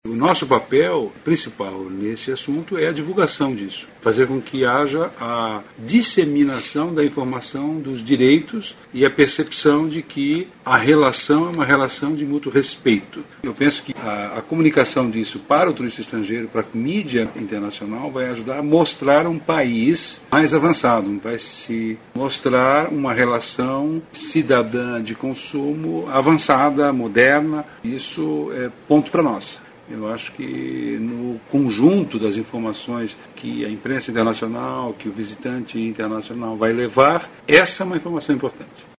aqui e ouça declaração na qual o secretário Vinícius Lummertz frisa a importância da orientação a consumidores.